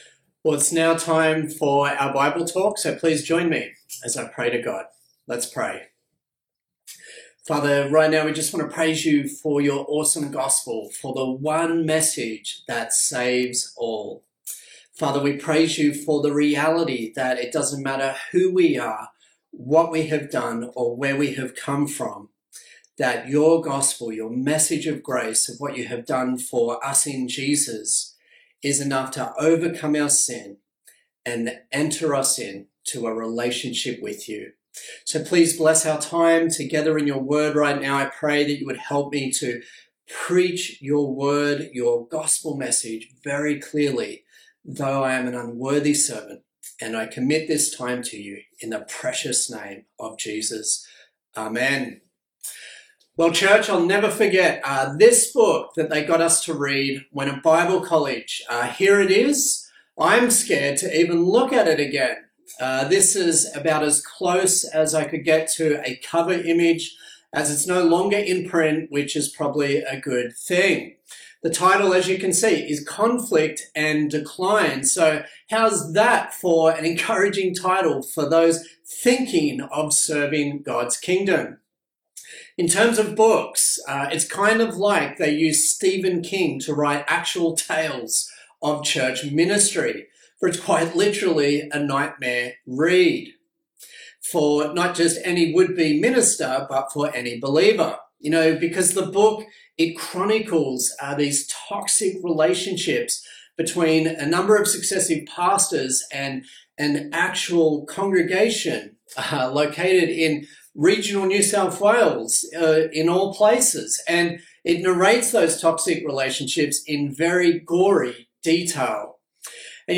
Acts Passage: Acts 15:1-35 Service Type: Sunday Morning